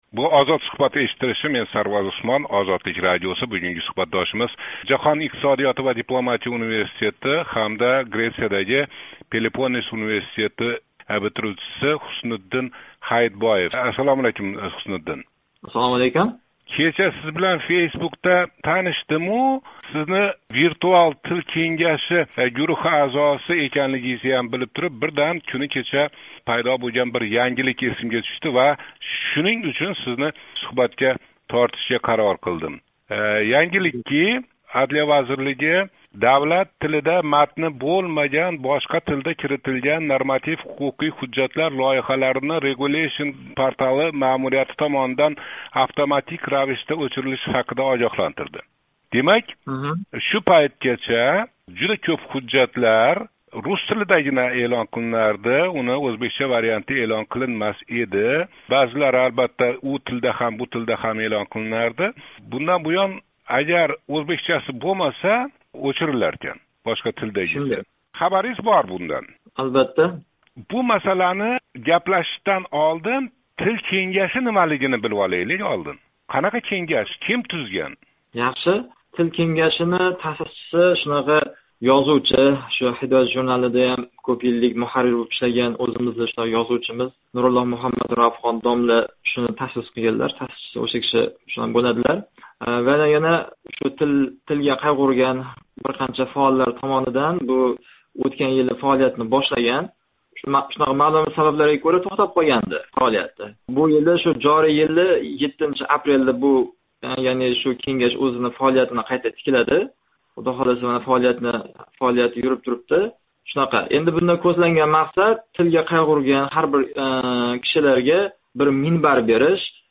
OzodSuhbat - Озодликнинг янги эшиттириши. OzodNazarдан фарқли ўлароқ, бу эшиттиришда экспертлар, таҳлилчилар эмас, куннинг у ёки бу воқеасининг ҳеч йўқ бир четига алоқаси бор ҳар қандай тингловчи иштирок этиши мумкин.